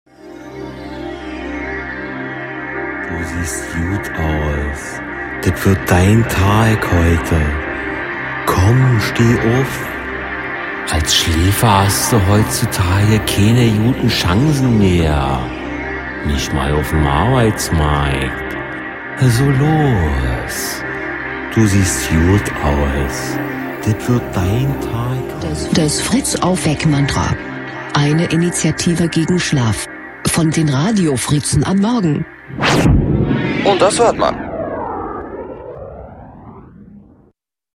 FritzAufweck-Mantra 21.02.17 (Schläfer) | Fritz Sound Meme Jingle